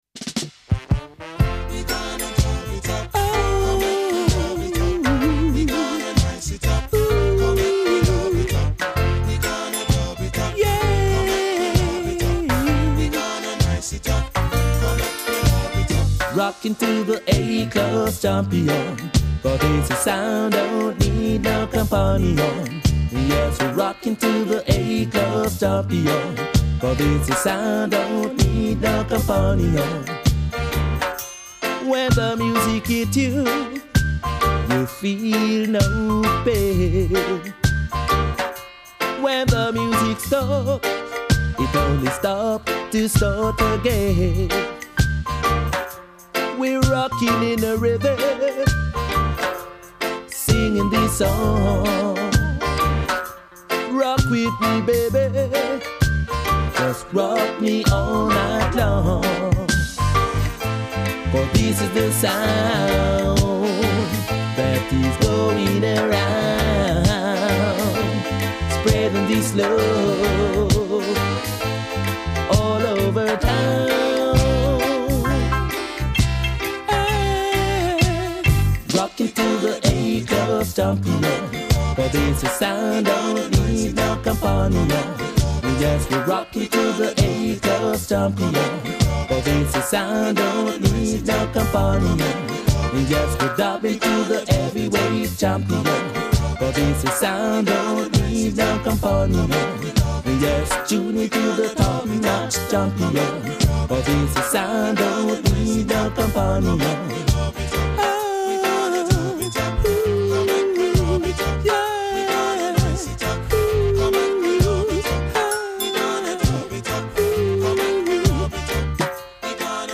DJ set
Reggae/Dub